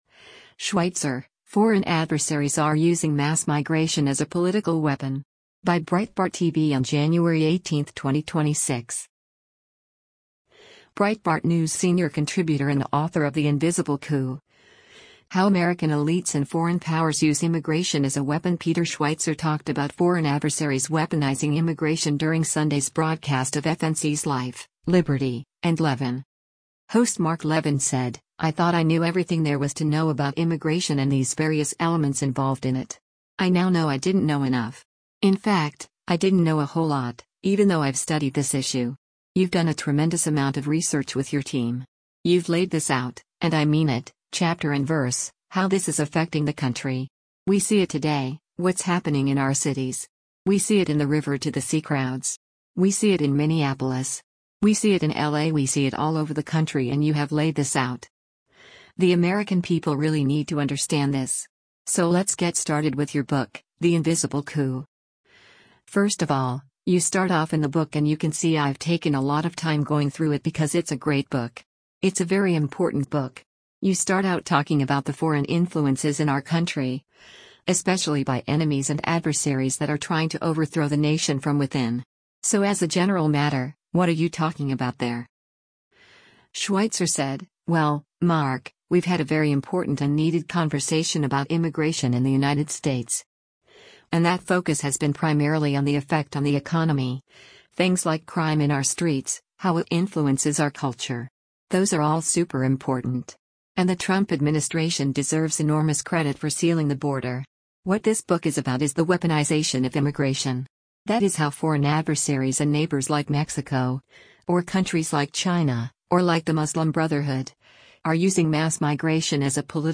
Breitbart News senior contributor and author of The Invisible Coup: How American Elites and Foreign Powers Use Immigration as a Weapon Peter Schweizer talked about foreign adversaries weaponizing immigration during Sunday’s broadcast of FNC’s “Life, Liberty, and Levin.”